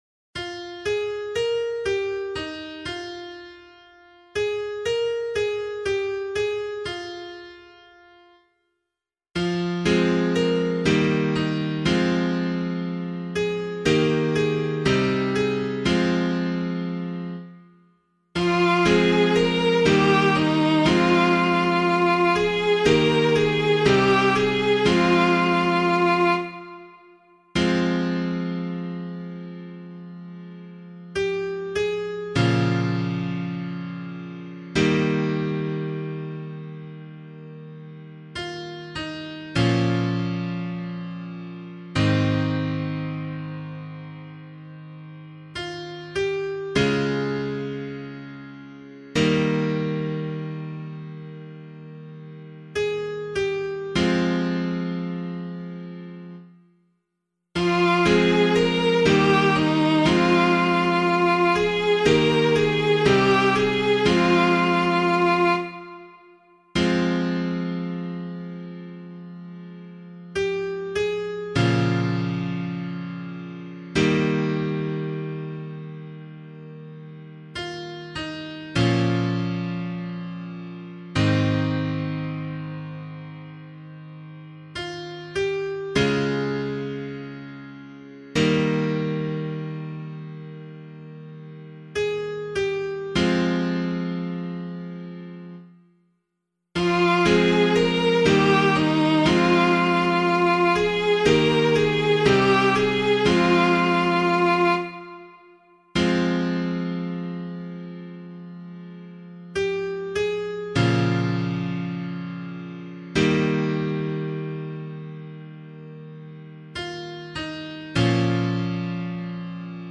005 Christmas Vigil Psalm [LiturgyShare 2 - Oz] - piano.mp3